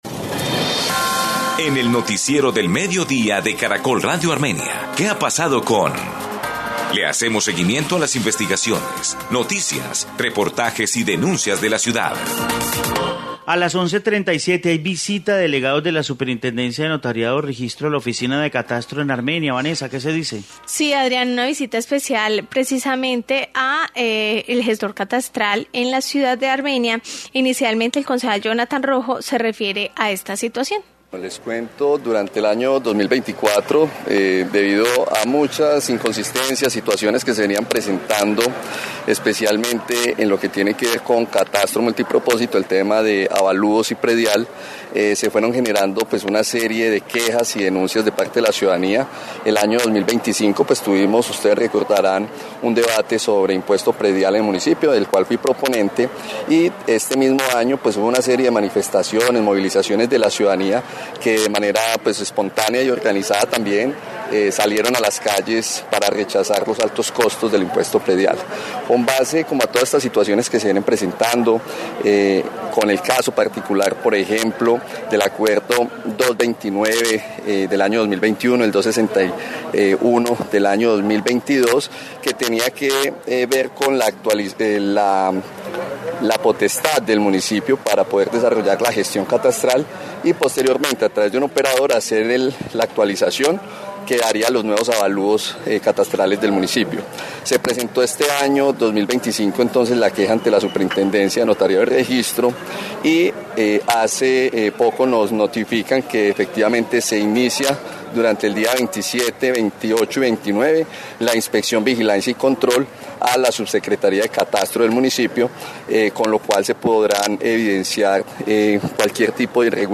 Informe sobre visita a oficina de catastro de Armenia